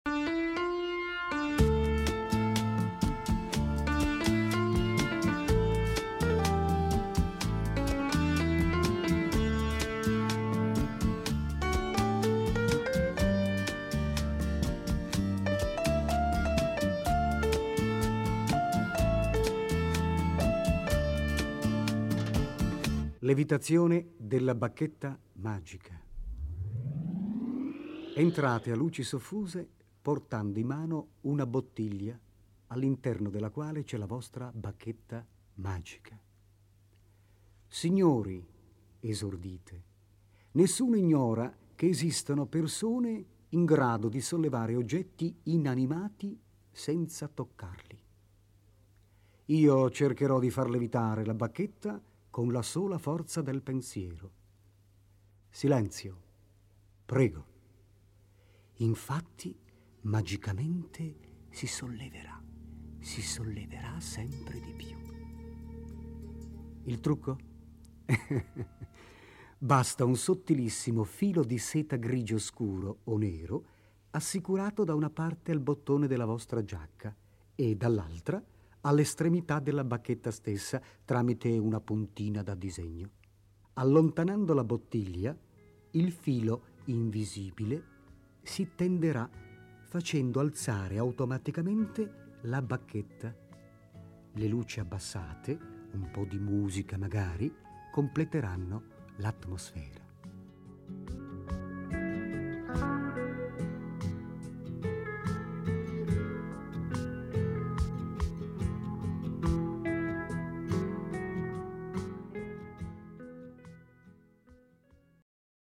Le ultime tre tracce erano altrettanti giochi di prestigio spiegati da Silvan su un sottofondo musicale.